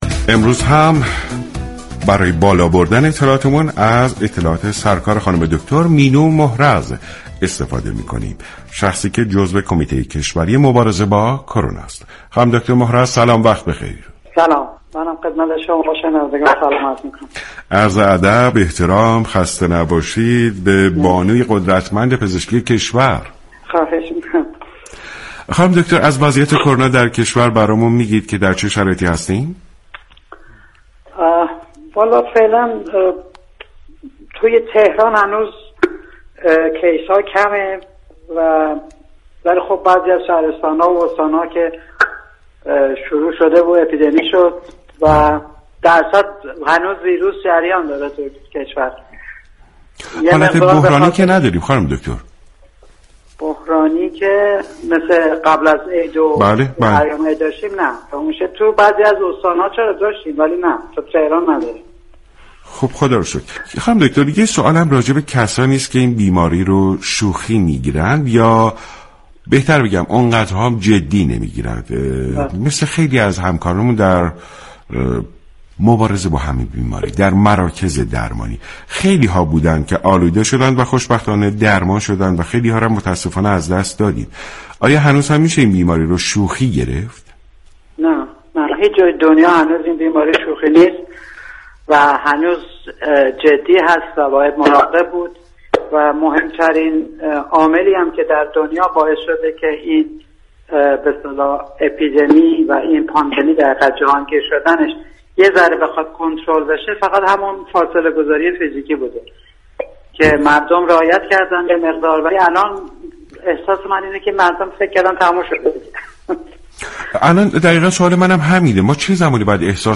شما می توانید از طریق فایل صوتی پیوست شنونده بخشی از برنامه سلامت باشیم رادیو ورزش كه شامل صحبت های متخصص بیماری های عفونی درباره كرونا و پاسخگویی به سوالات عموم است؛ باشید.